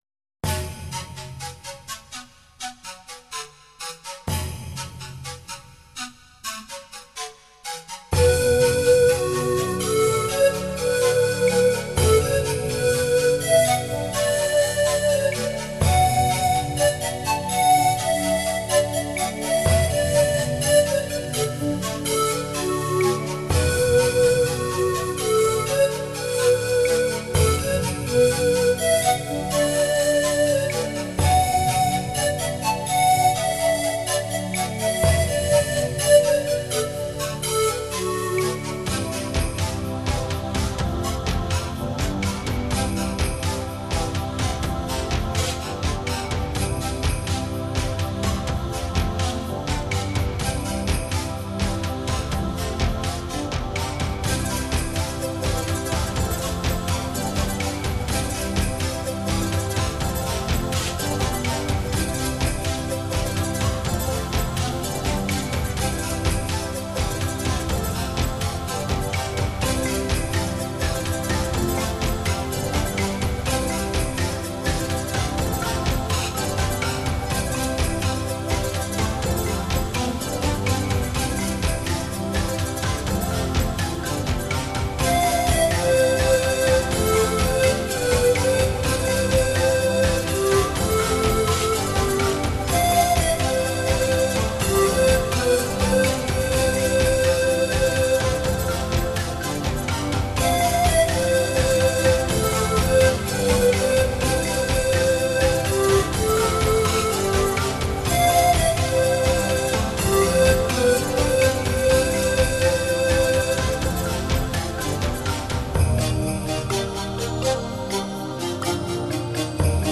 Музыка релакс New age Relax Нью эйдж